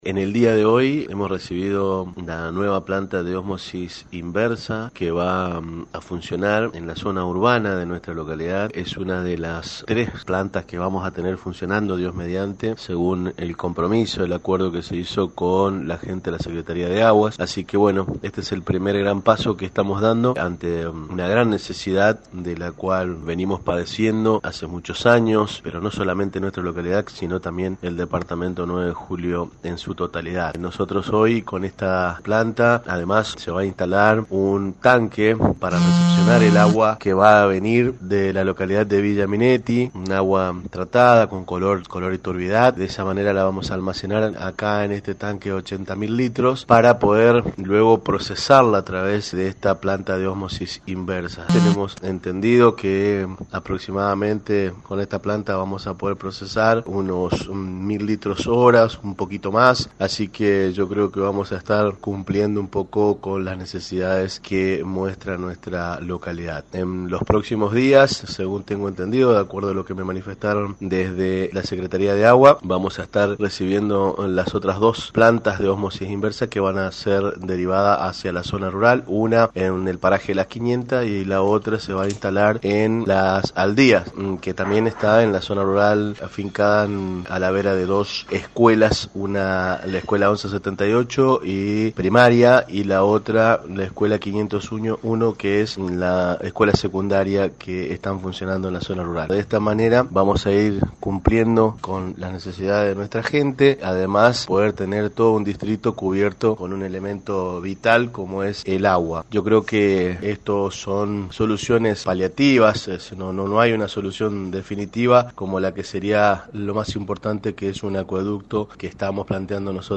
Todos los detalles en la voz de Fernando Díaz, presidente comunal de Gregoria Pérez de Denis: